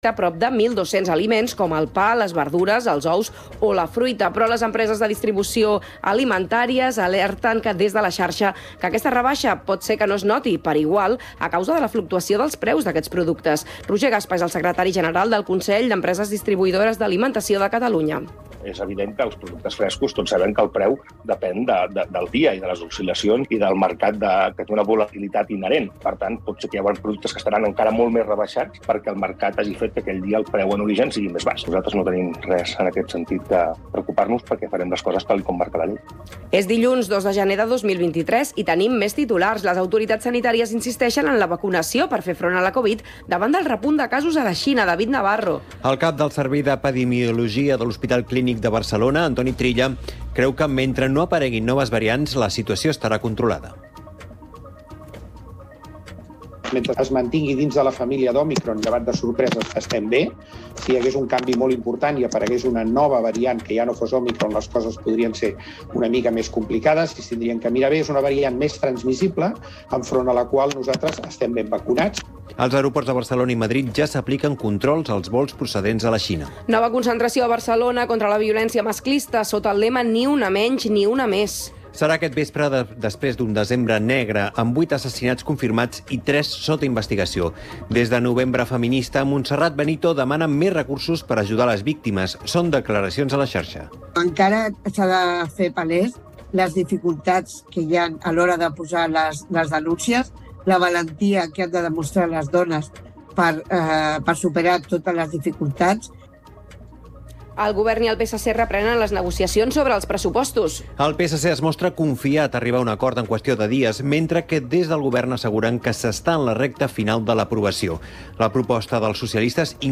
Informatiu radiofònic que dóna prioritat a l’actualitat local.